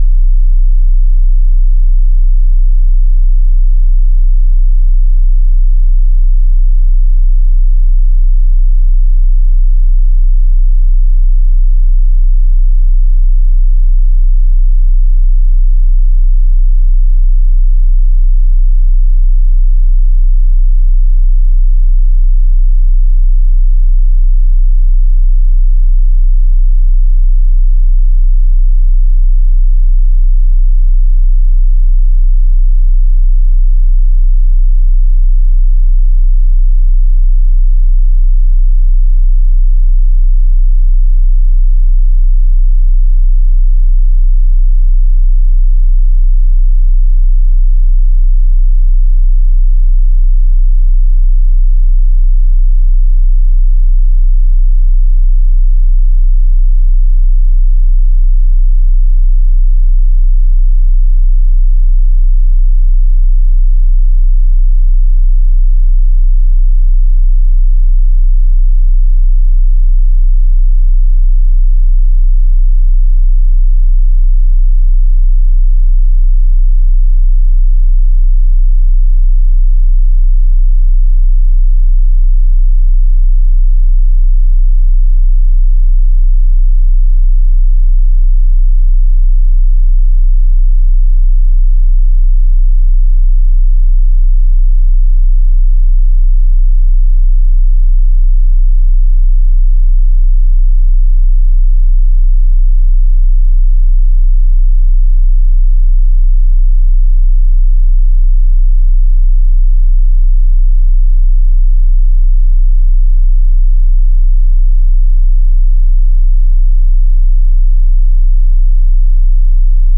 音の測定には、周波数一定、音圧一定の音声を流し続けます。
(2) ウーファー確認用 バスレンジ
モノラル出力、音圧はすべて同一です。各周波数、4分間の再生です。
31.5Hz